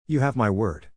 発音：/juː ˈhæv maɪ ˈwəːrd/